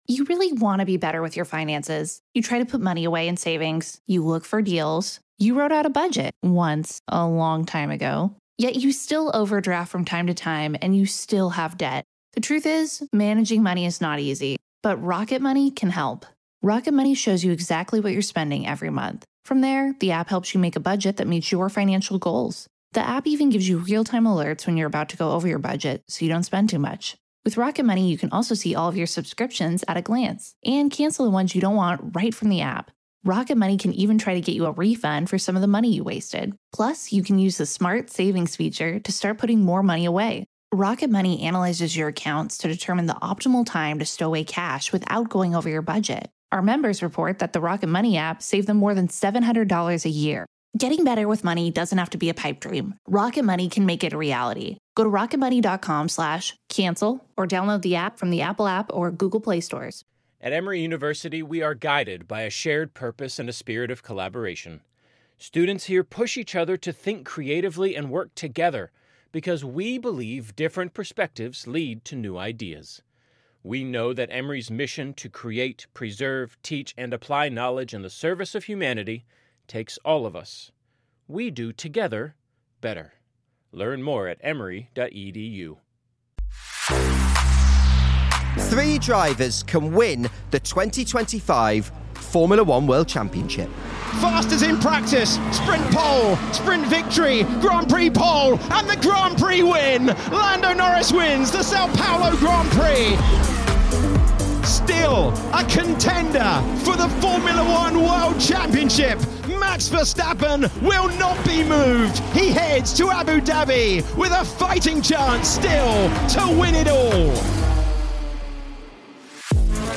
in the Yas Marina paddock to explain what it takes to achieve motorsport’s biggest prize.